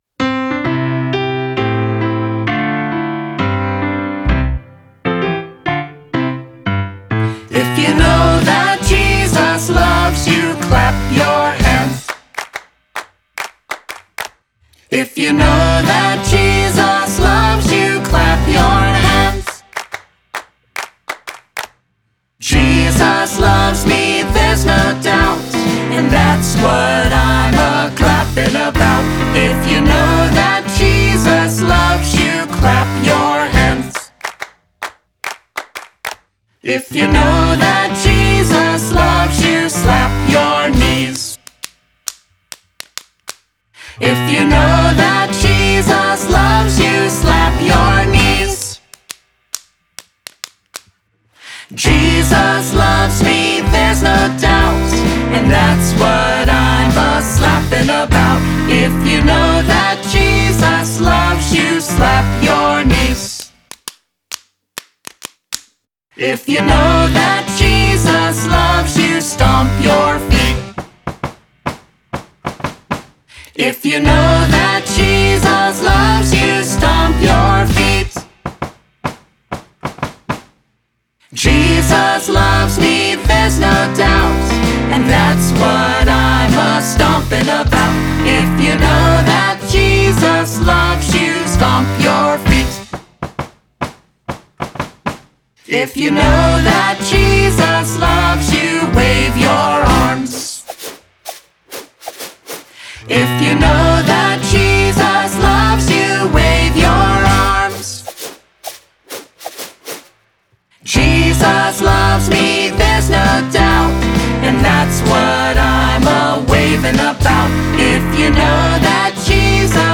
vocals, guitar, banjo, harmonica
upright bass
percussion
piano, trumpet, flugelhorn